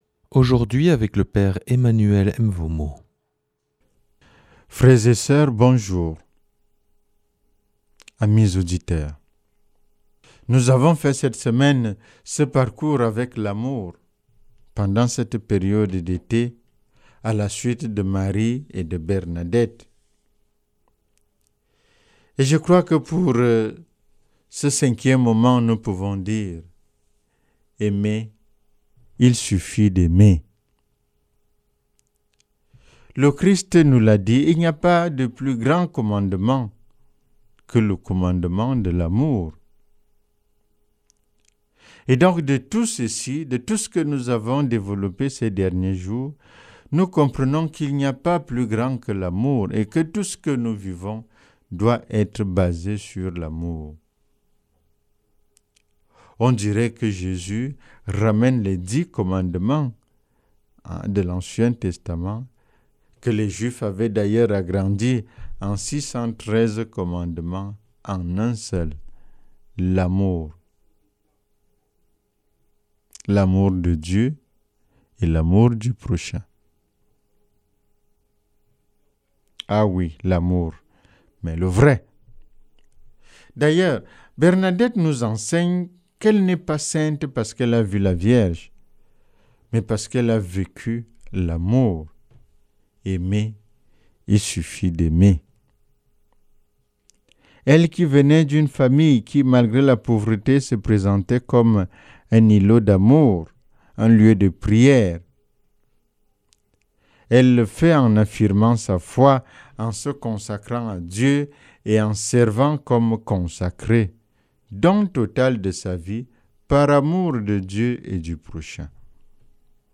vendredi 5 juillet 2024 Enseignement Marial Durée 10 min